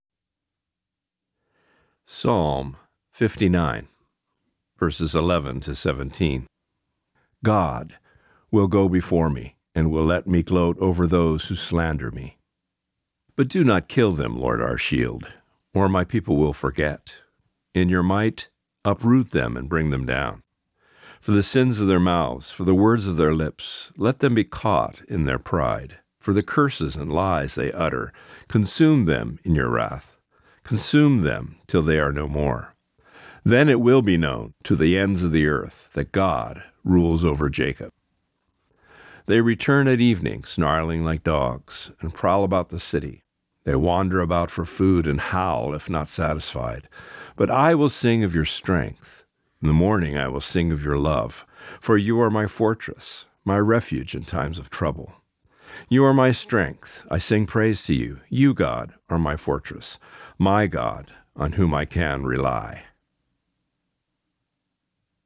Reading:  Psalm 59:10-17 (NIV)*